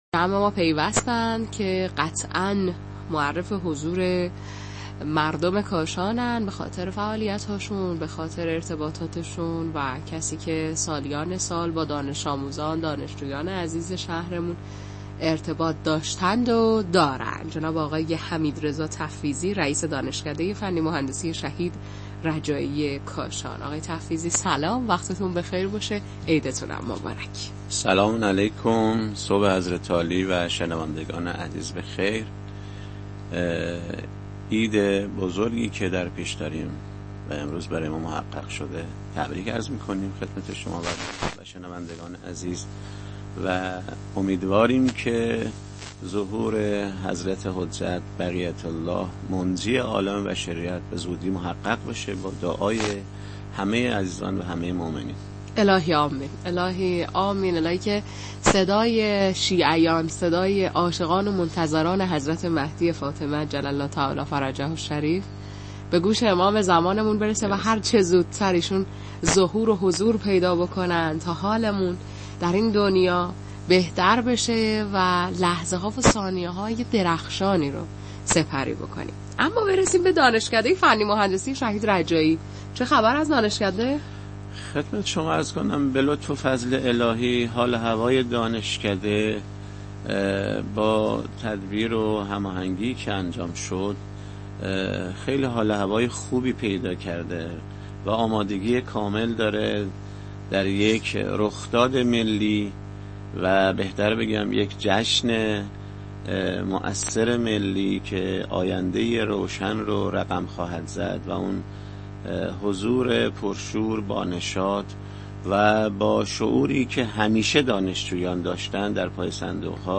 مصاحبه رئیس دانشکده با رادیو کاشان